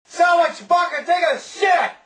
Sounds lick Chewbacca Taking A Shit